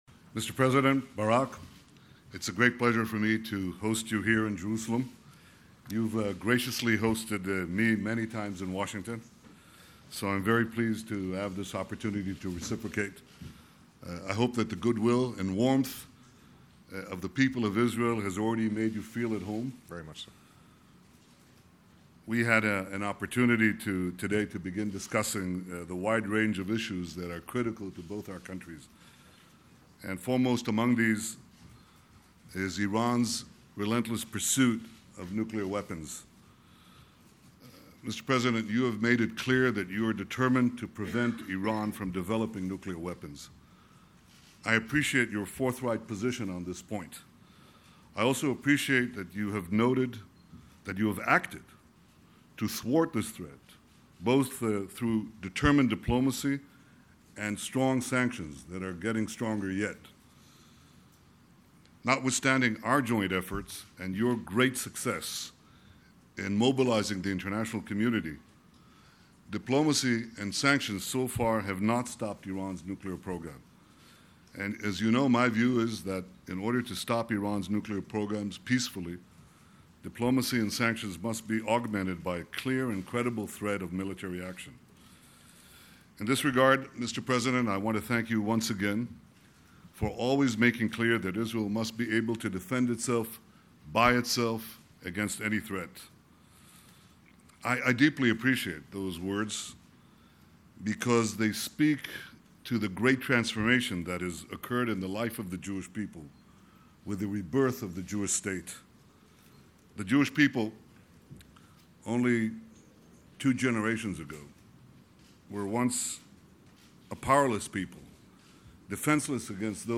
U.S. President Barack Obama and Israeli Prime Minister Benjamin Netanyahu hold a joint press conference